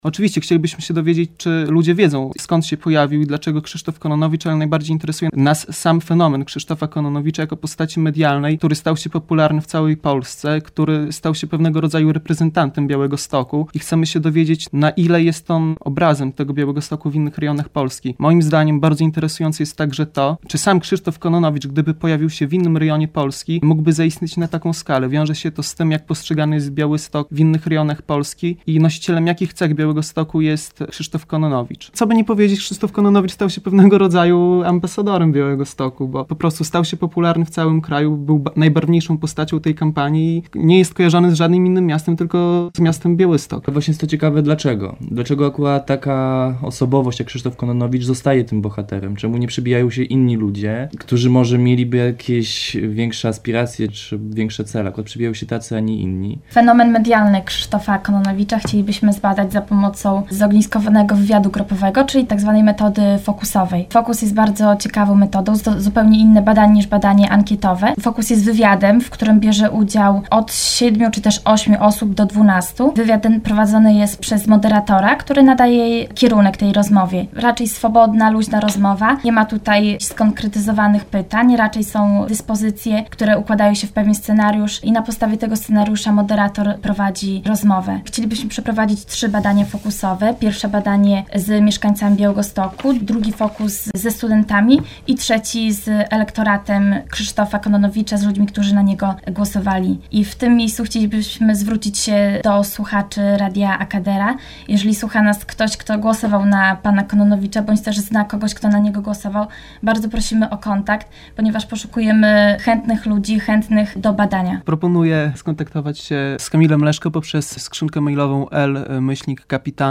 Materiał Radia Akadera